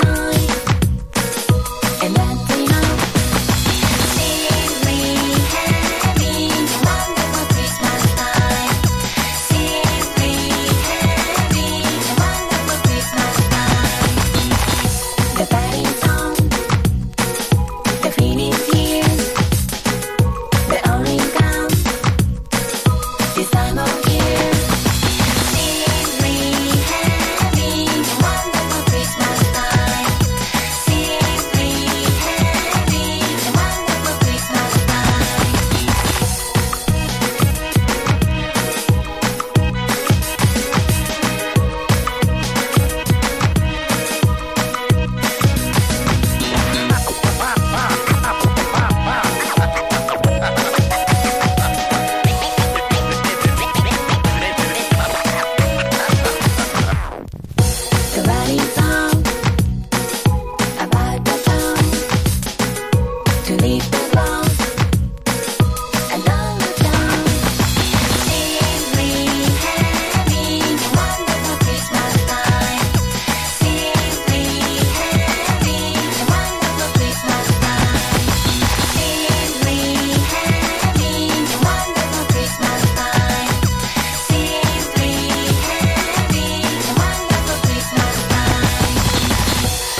キャッチーなベースラインにスクラッチも配したウルトラキュートな1曲。
BREAK BEATS / BIG BEAT